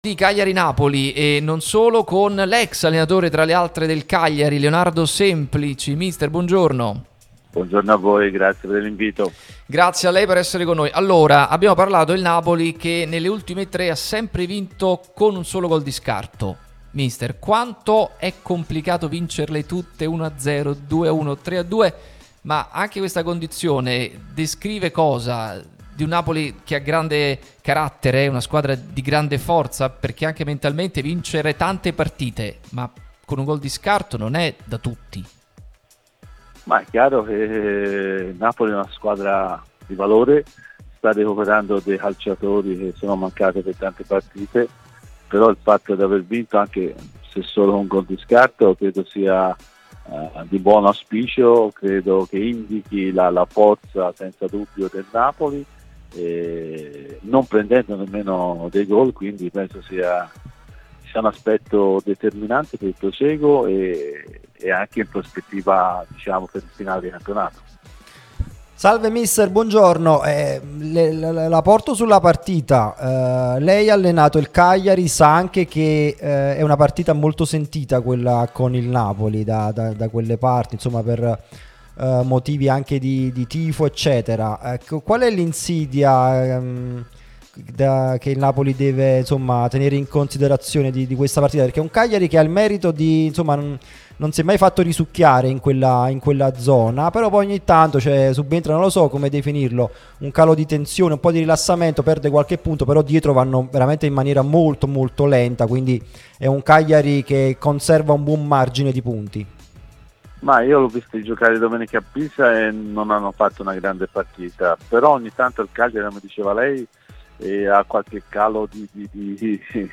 Leonardo Semplici è stato nostro ospite su Radio Tutto Napoli, l'unica radio tutta azzurra e sempre live, che puoi seguire sulle app gratuite (per Iphone o per Android, Android Tv ed LG), in auto col DAB o qui sul sito anche in video.